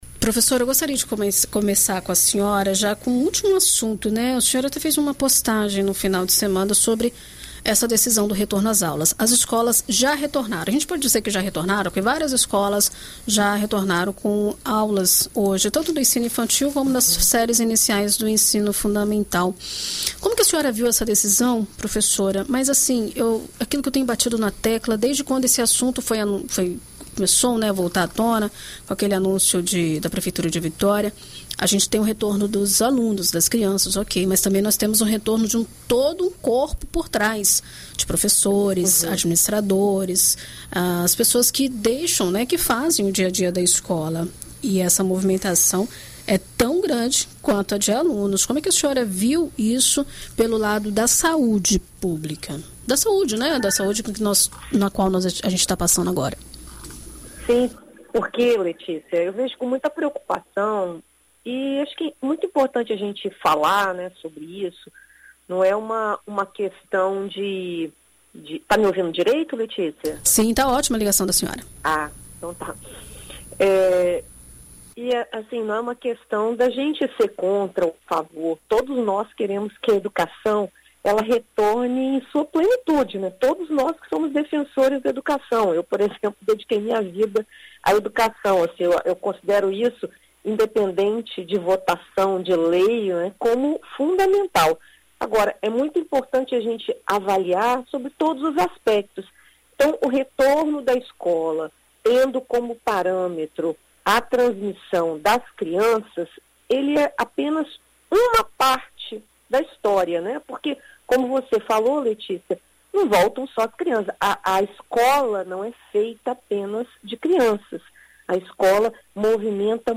Em entrevista à BandNews FM, ela ressalta, no entanto, que as vacinas da Coronavac que chegarem devem ir para quem está há mais tempo aguardando a segunda dose.